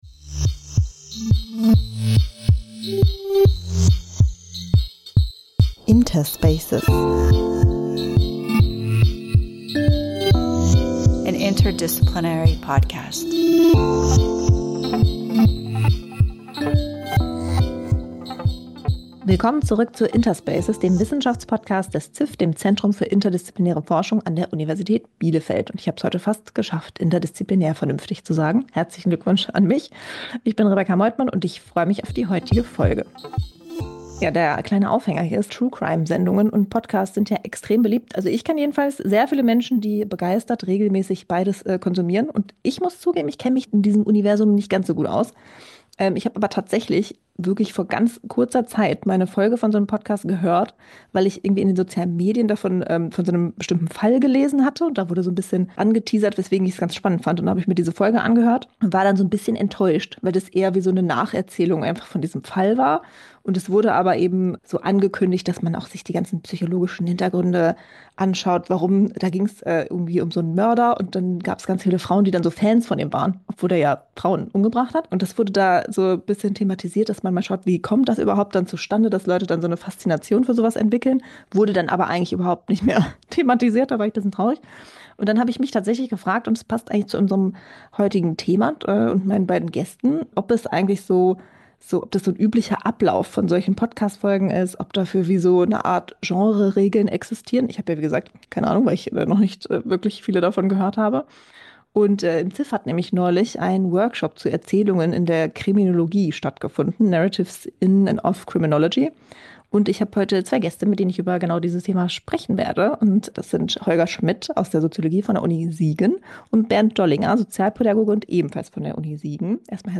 Darum geht es in dieser Folge im Gespräch